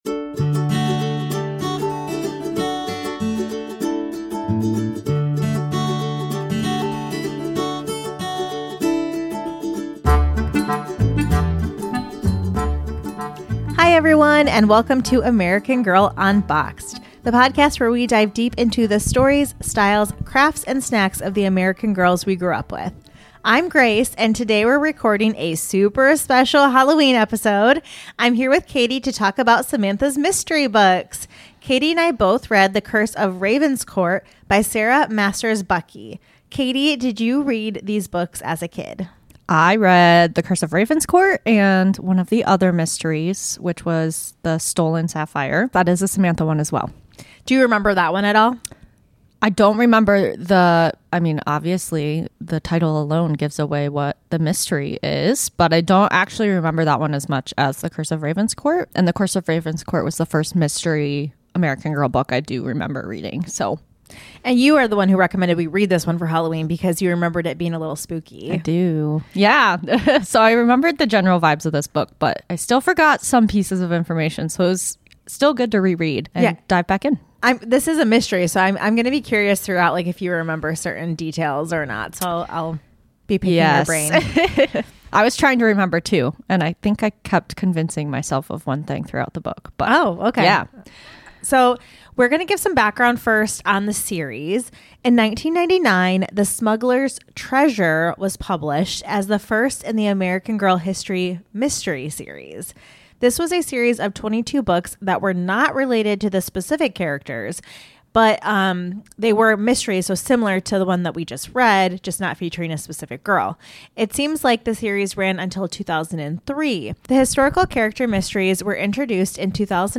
The hosts review The Curse of Ravenscourt: A Samantha Mystery from American Girl.